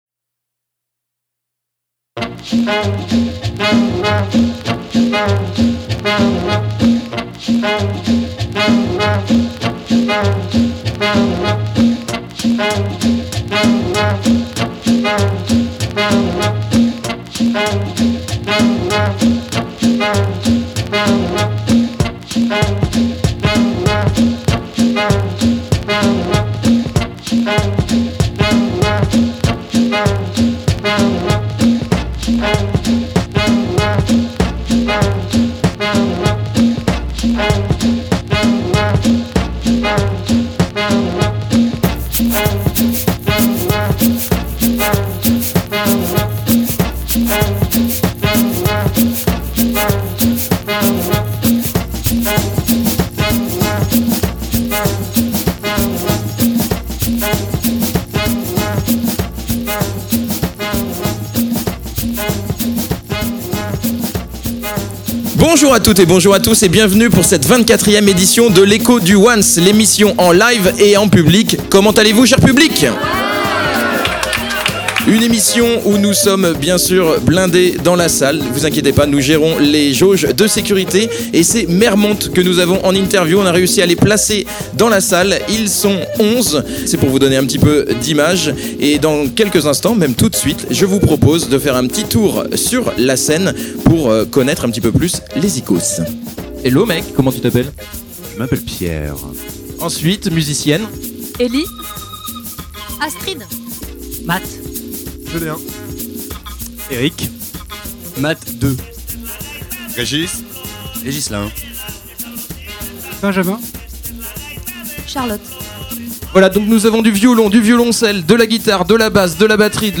Enregistrement en public